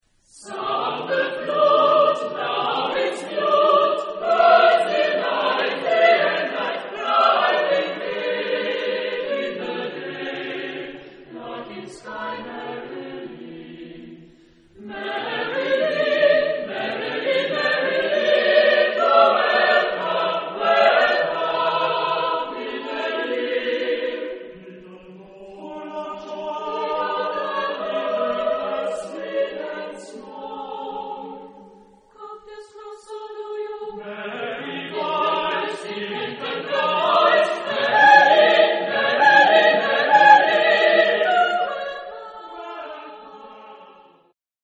Genre-Style-Form: Contemporary ; Secular ; Partsong
Mood of the piece: joyous ; lively
Type of Choir: SATB  (4 mixed voices )
Tonality: various